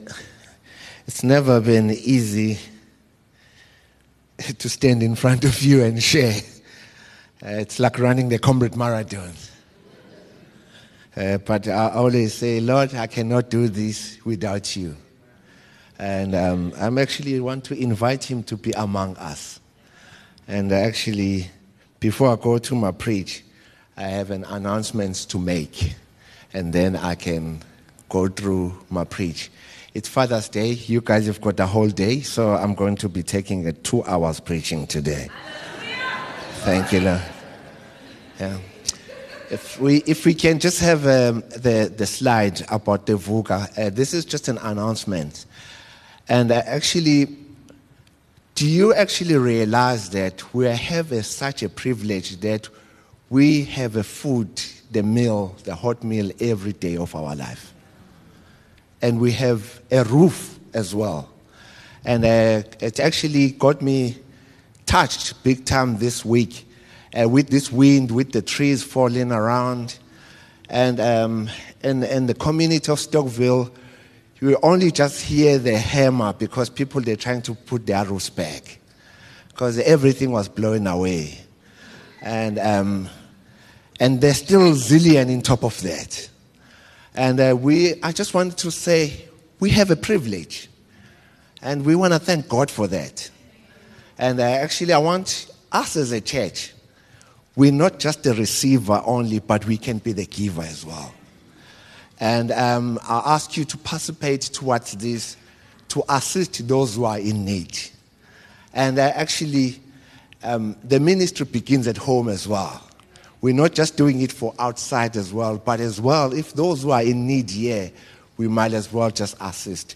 Upper Highway Vineyard Sunday messages 15 Jun Your time is not wasted! 27 MIN Download